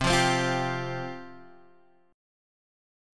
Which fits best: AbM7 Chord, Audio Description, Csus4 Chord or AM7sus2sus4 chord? Csus4 Chord